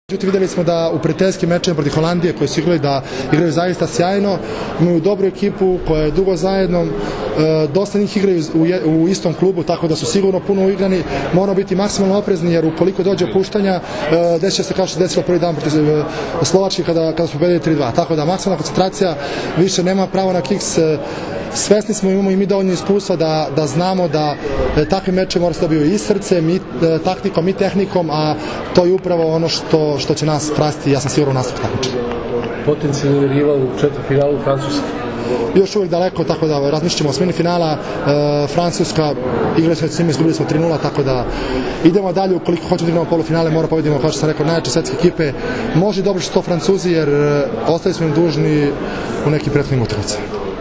IZJAVA ALEKSANDRA ATANASIJEVIĆA